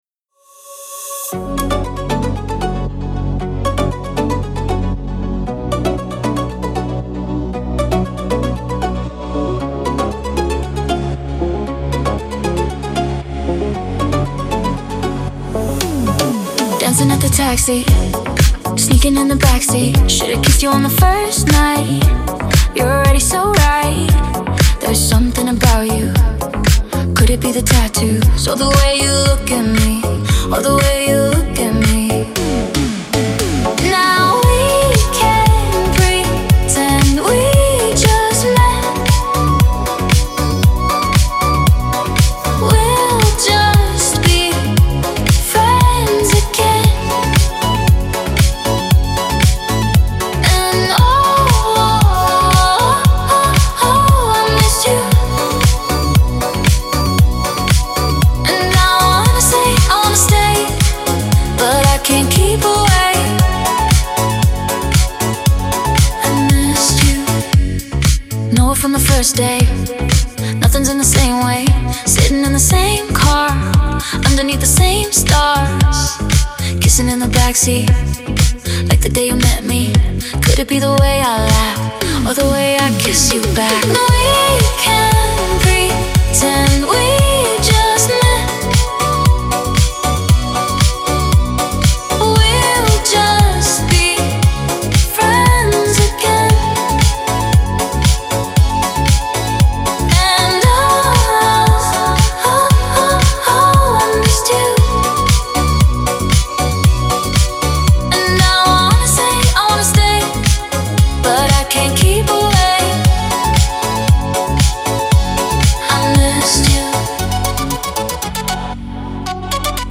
диско
танцевальная музыка
pop
dance